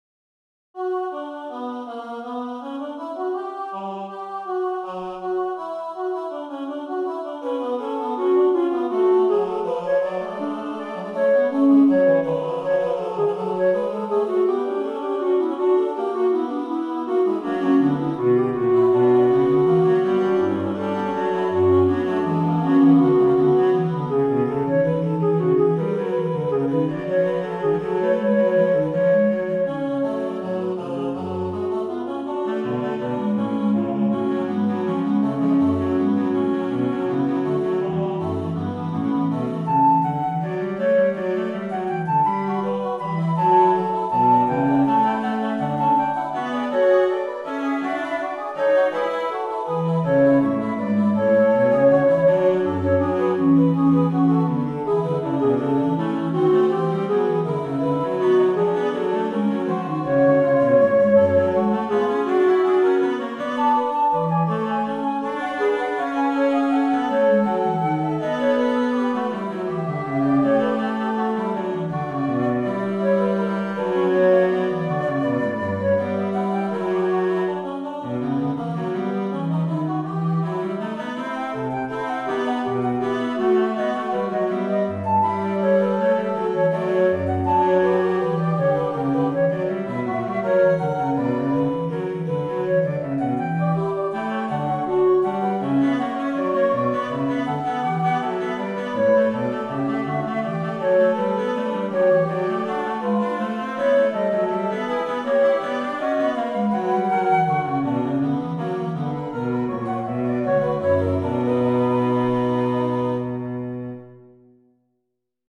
In an effort to keep the voices clearly differentiated, I’ve ‘orchestrated’ the piece for the unlikely sounding combination of (what my computer tells me is) alto flute, human voice and ’cello.